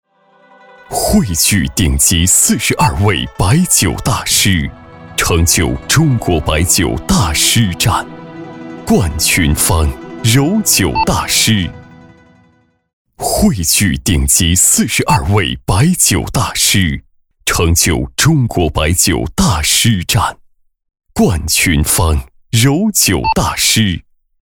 B男98号
【广告】柔酒大师